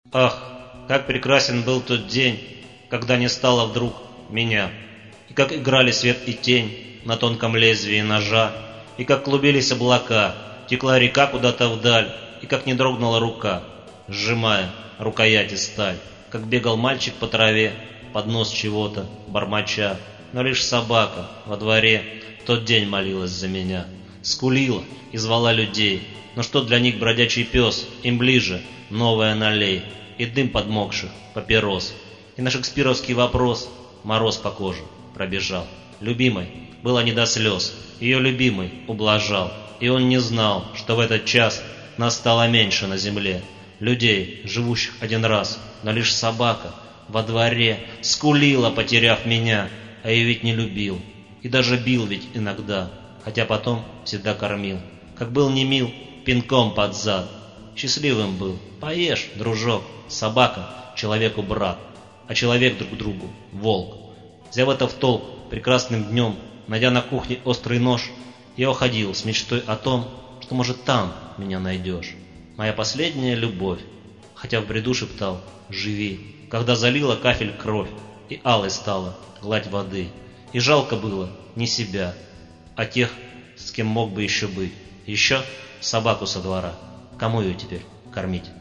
3.Собачья боль (стих)